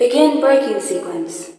VOICES / COMPUTER
BRAKE.WAV